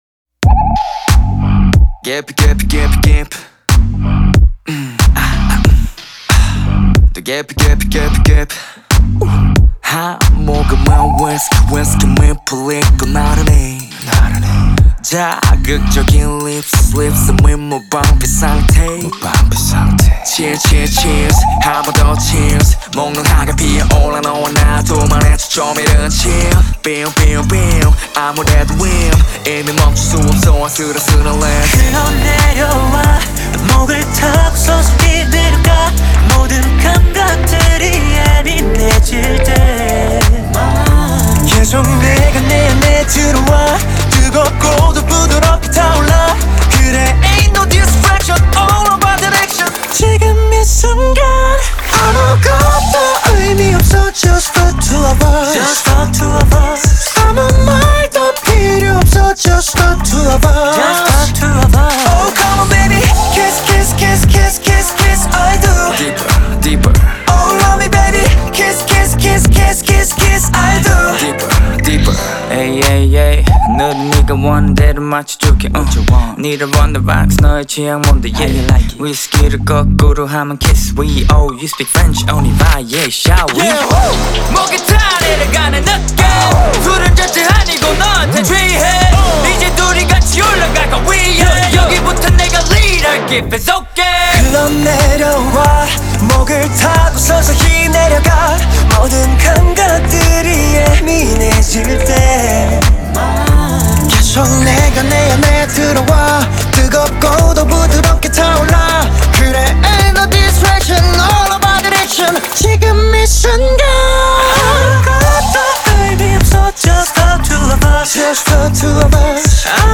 Korean Music Kpop